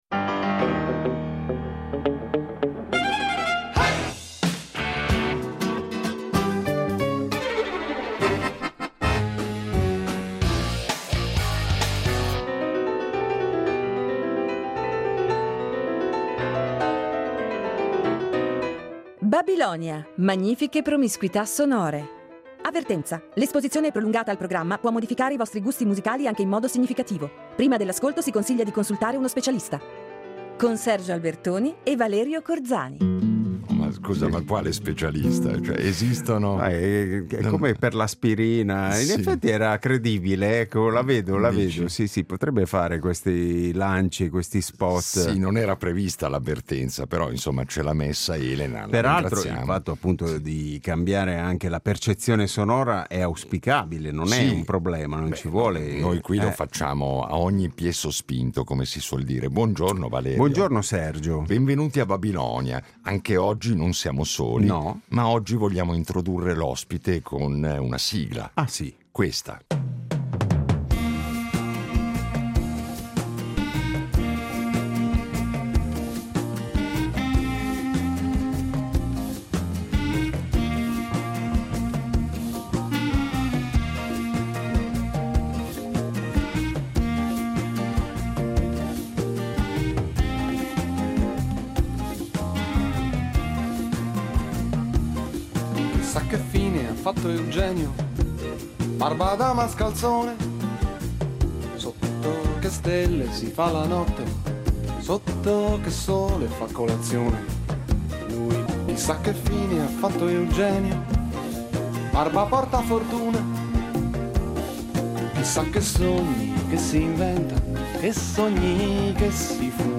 Magnifiche promiscuità sonore
Ecco perché ci sembra doveroso recuperarlo, di tanto in tanto, riunendolo in puntate particolarissime perché svincolate dall’abituale scansione di rubriche e chiacchierate con gli ospiti. Itinerari ancor più sorprendenti, se possibile, che accumulano in modo sfrenato i balzi temporali e di genere che caratterizzano da sempre le scalette di Babilonia .